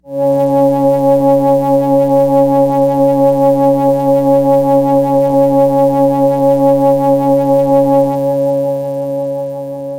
描述：通过Modular Sample从模拟合成器采样的单音。
Tag: CSharp5 MIDI音符-73 Korg的-Z1 合成器 单票据 多重采样